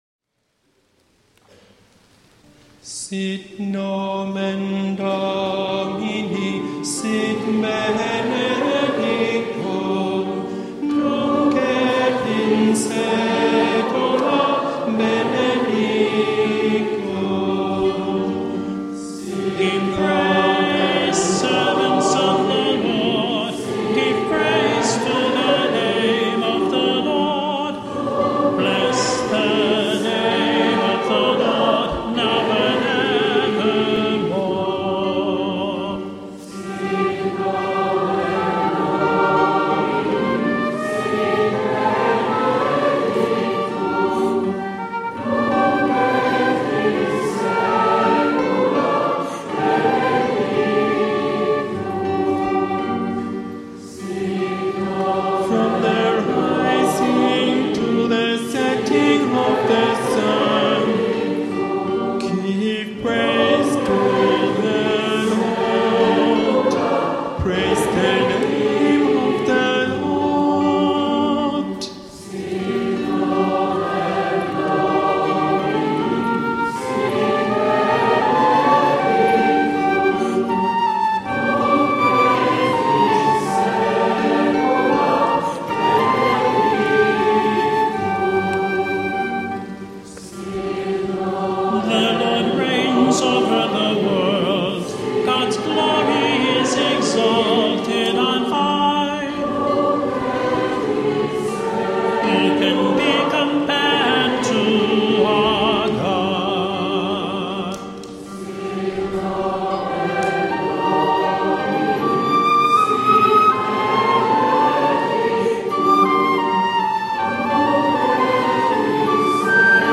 spievaná modlitba